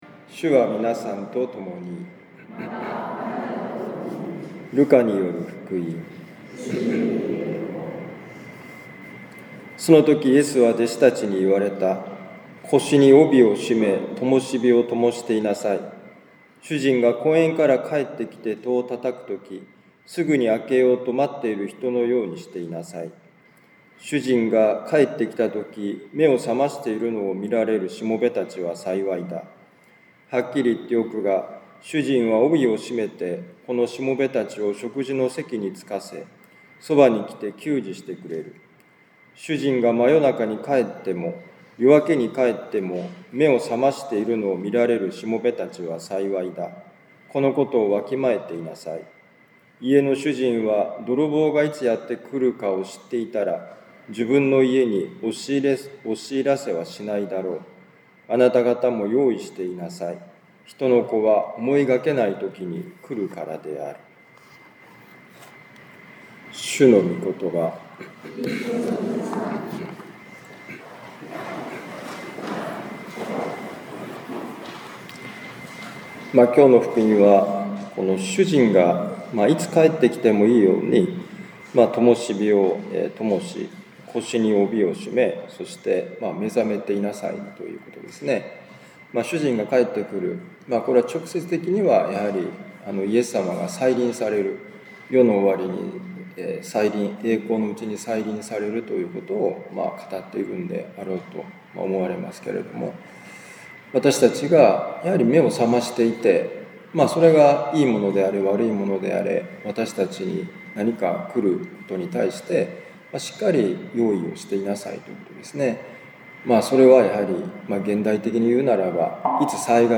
ルカ福音書12章32-48節「記憶」2025年8月10日年間第19主日ミサカトリック長府教会